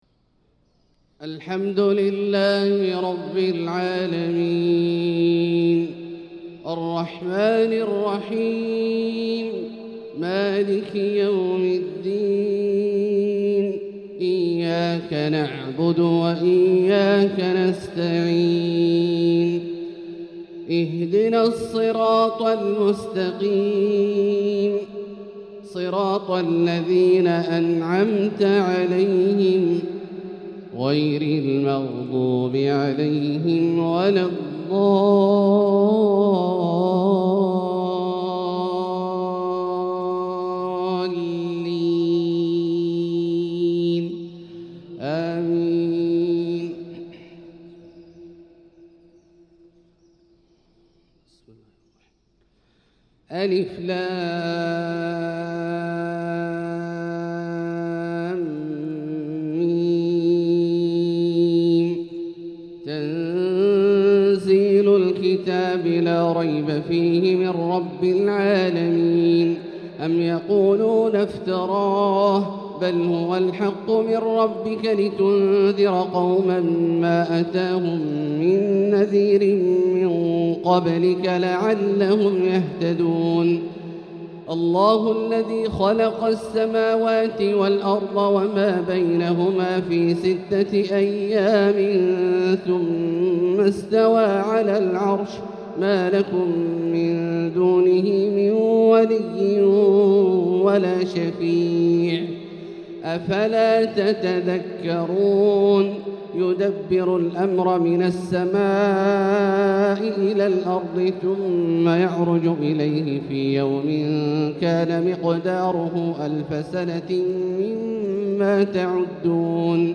تلاوة لسورتي السجدة والإنسان | فجر الجمعة 6-8-1445هـ > ١٤٤٥هـ > الفروض - تلاوات عبدالله الجهني